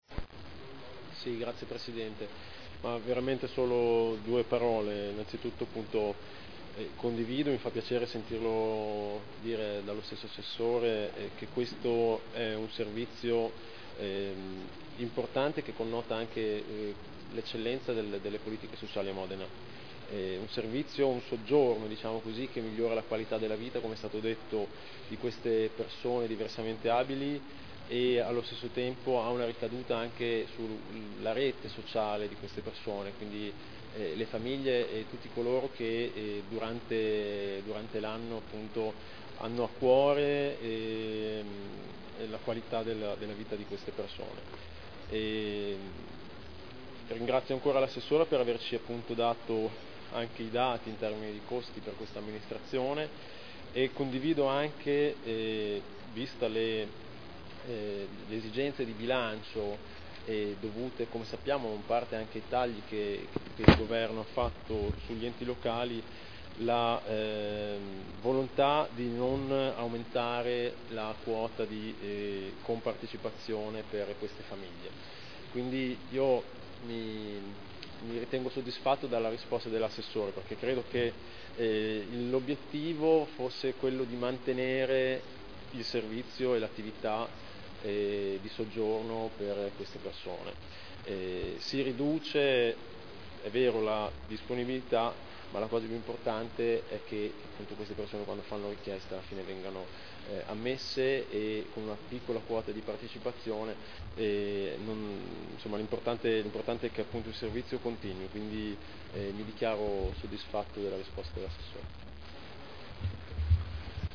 Stefano Rimini — Sito Audio Consiglio Comunale